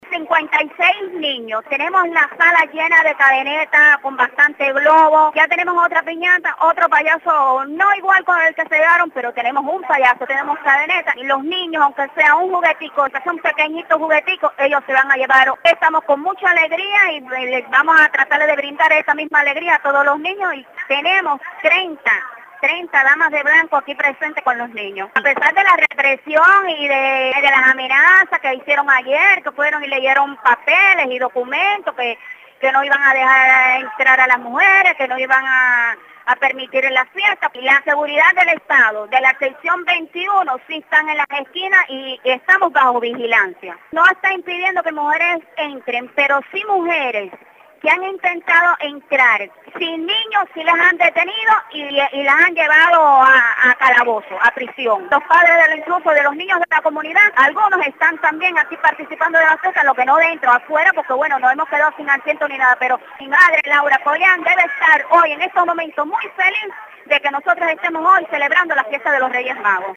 Declaraciones a Radio Martí